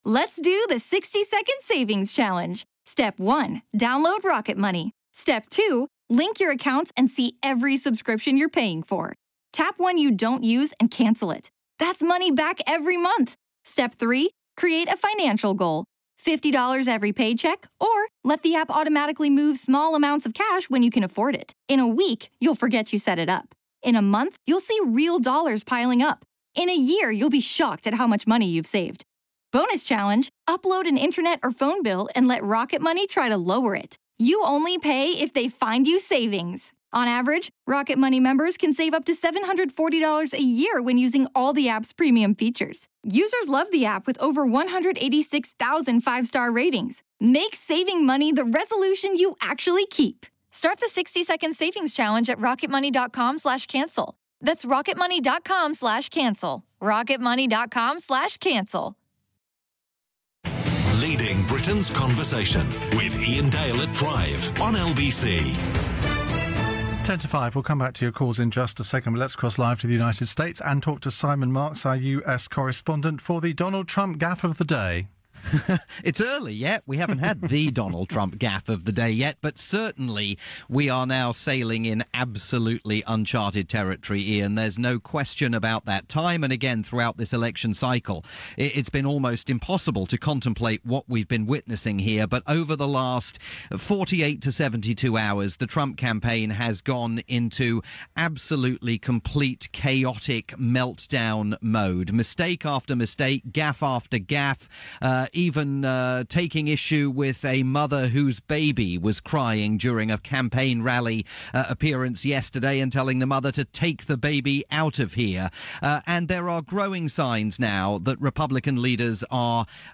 report via LBC's Iain Dale At Drive.